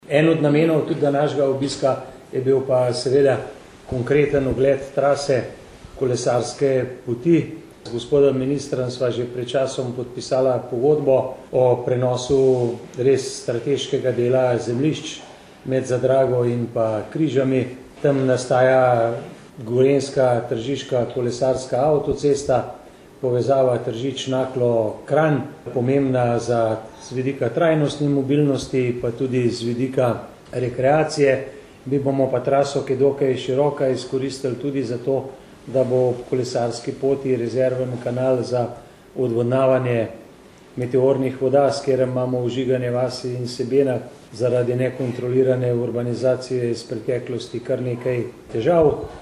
izjava_zupanobcinetrzicmag.borutsajovicobobiskuministra.mp3 (1,2MB)